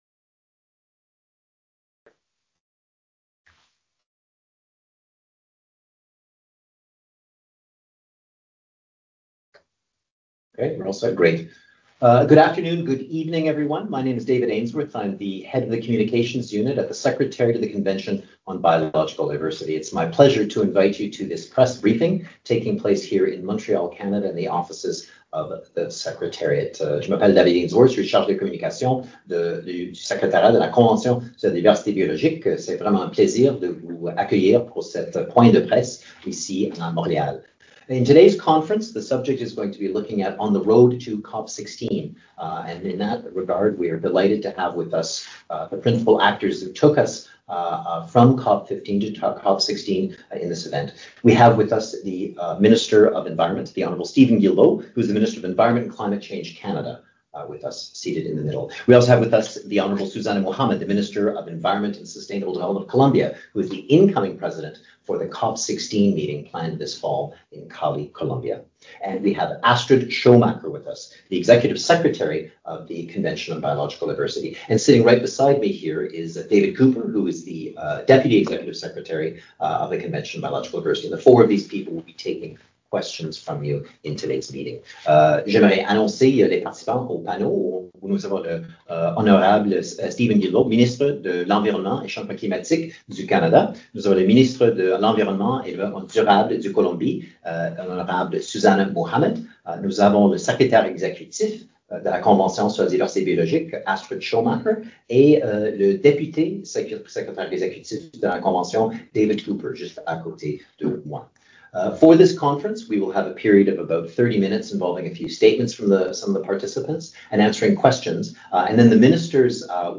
Press conference Audio l COP 16 Canada, Colombia & the Convention on Biological Diversity - 21 August 2024
In a joint press conference held in the office of the Secretariat of the CBD, the Ministers outlined their perspectives on how “peace with nature”—the theme that Colombia crafted for COP 16— can be achieved and called on the Parties to the Convention to accelerate the implementation of the KMGBF—the world’s masterplan to halt and reverse biodiversity loss through 23 targets that must be achieved by 2030.